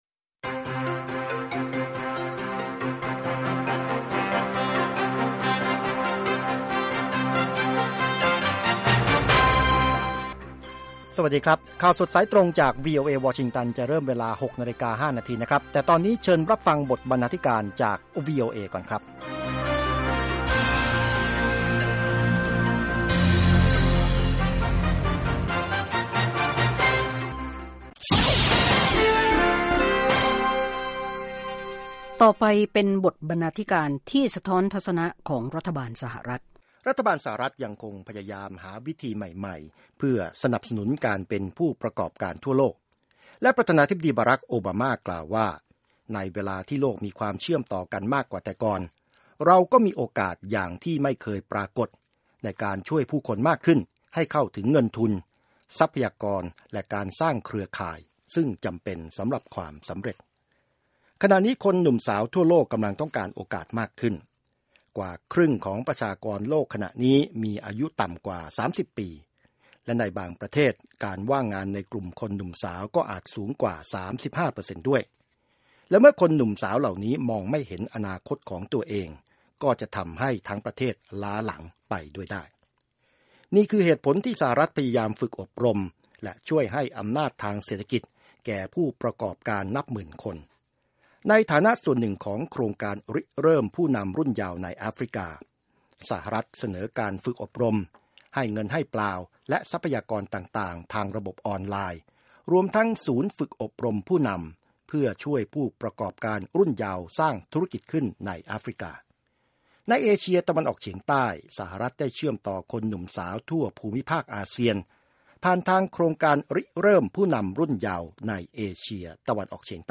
ข่าวสดสายตรงจากวีโอเอ ภาคภาษาไทย 6:00 – 6:30 น พุธ ที่ 20 พฤษภาคม 2558